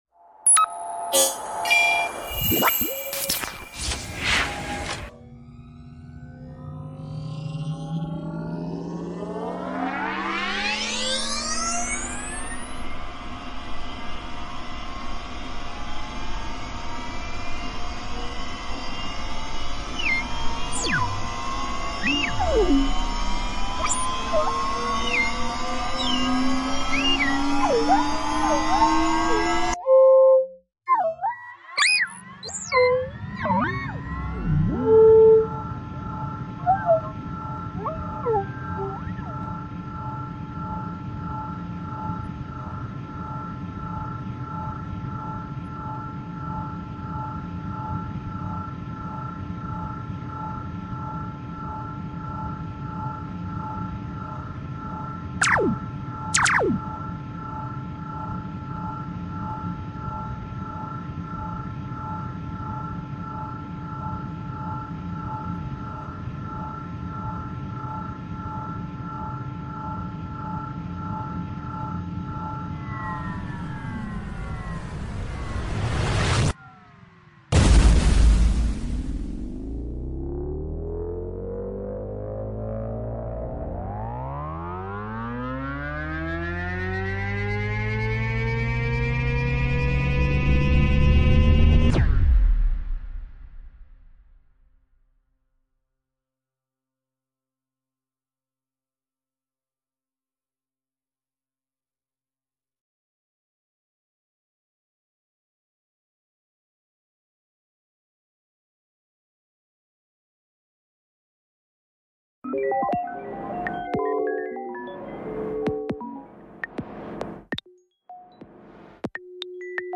Royalty Free Sci Fi Sounds II sound effects free download